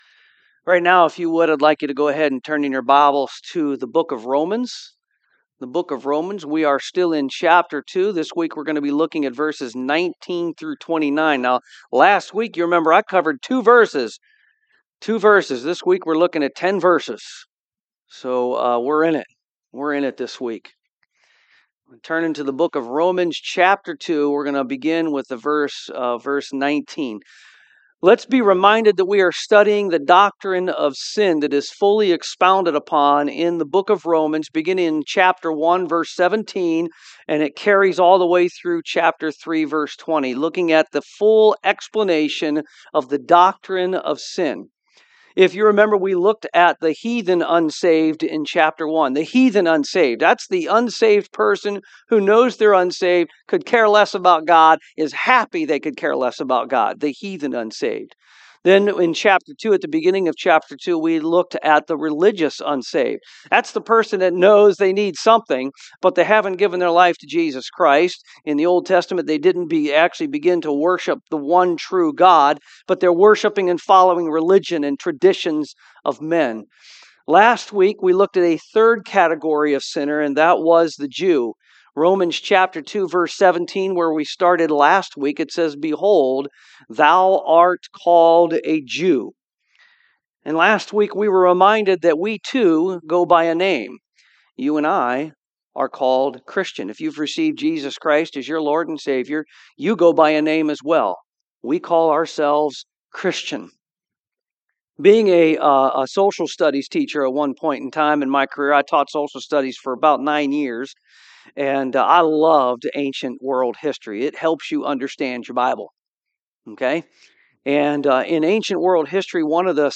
Romans 2:19-29 Service Type: AM Are you practicing rituals or a relationship with Jesus Christ?